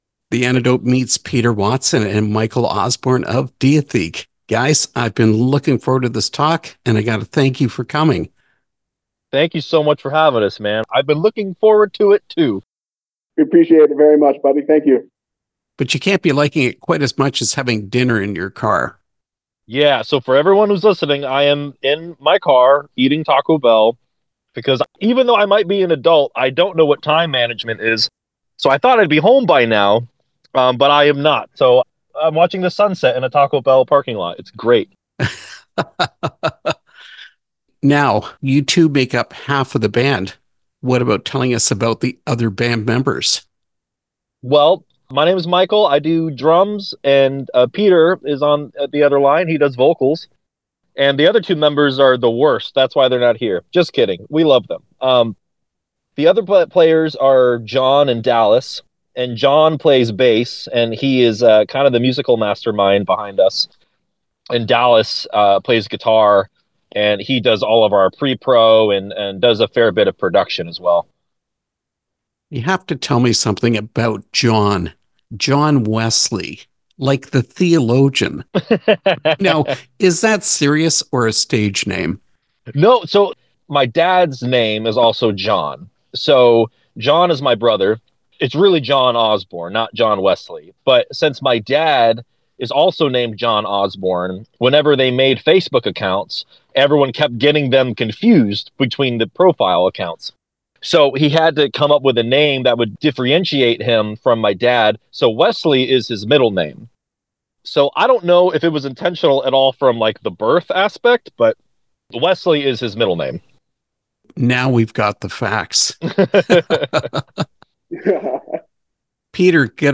Interview with Diatheke
diatheke-interview.mp3